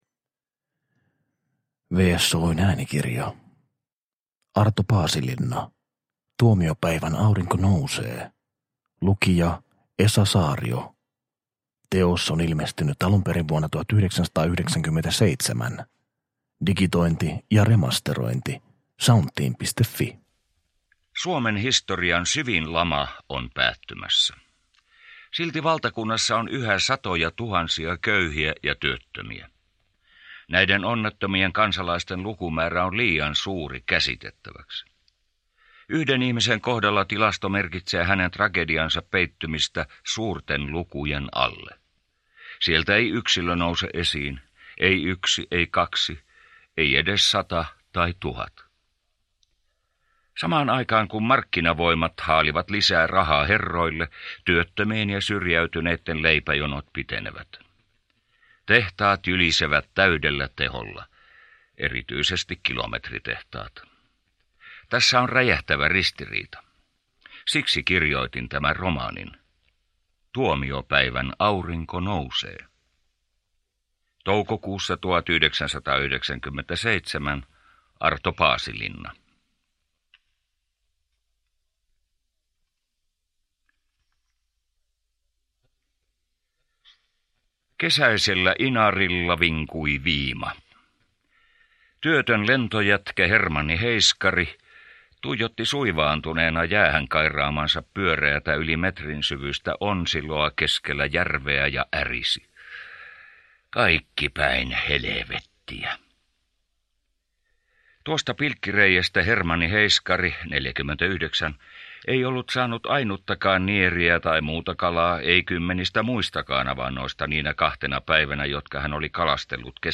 Tuomiopäivän aurinko nousee – Ljudbok